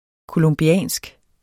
colombiansk adjektiv Bøjning -, -e Udtale [ kolɔmˈbjæˀnsg ] Betydninger fra Colombia; vedr. Colombia eller colombianerne